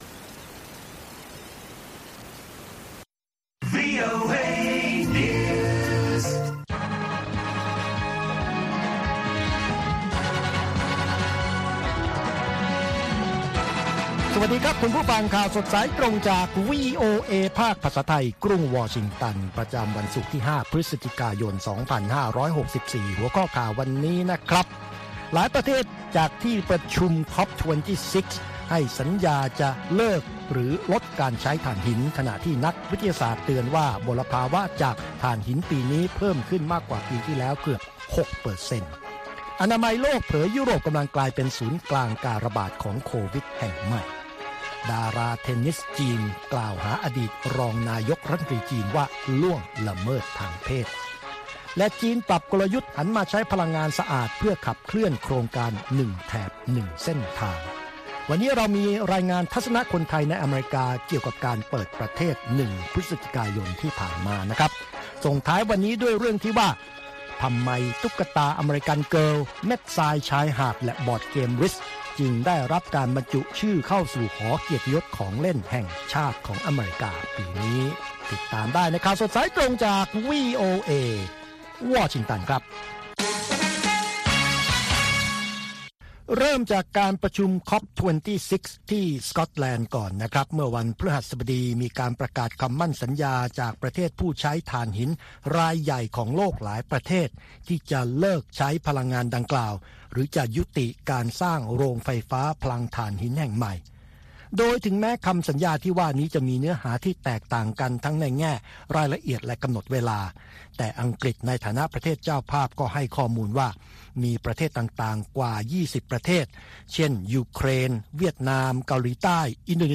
ข่าวสดสายตรงจากวีโอเอ ภาคภาษาไทย ประจำวันศุกร์ที่ 5 พฤศจิกายน 2564 ตามเวลาประเทศไทย